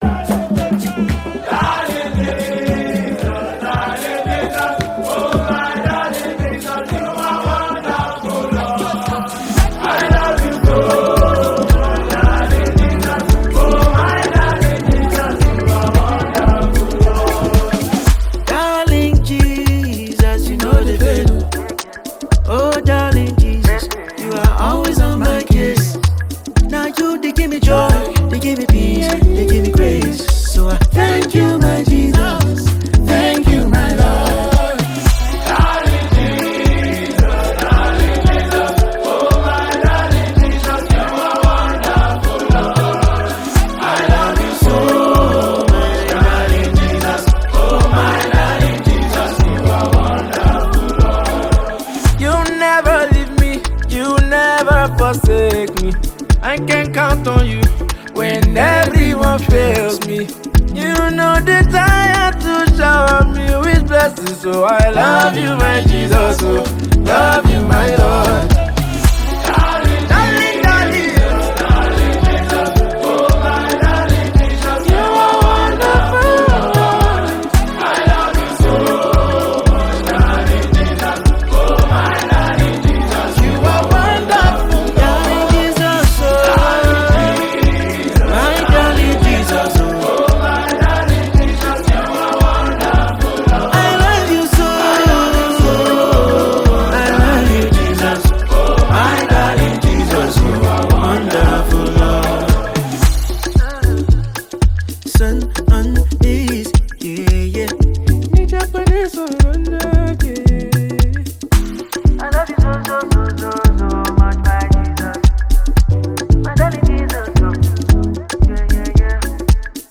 worship leader